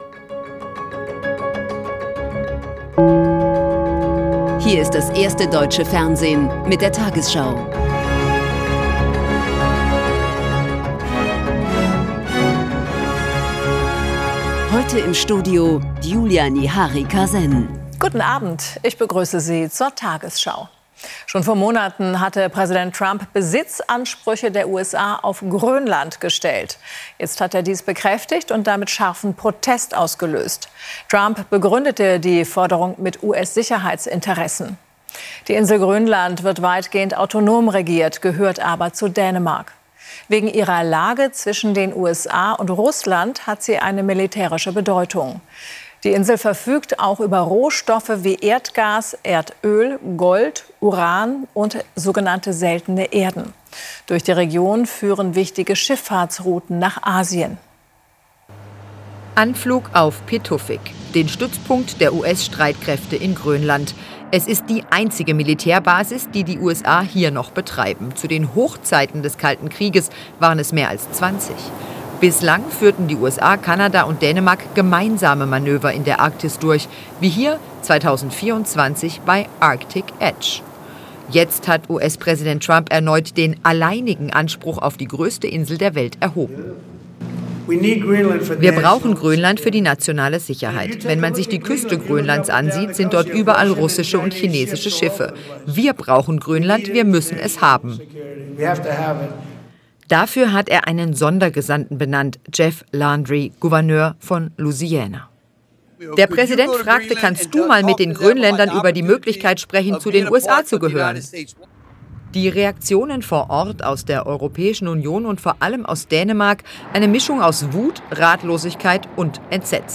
tagesschau 20:00 Uhr, 23.12.2025 ~ tagesschau: Die 20 Uhr Nachrichten (Audio) Podcast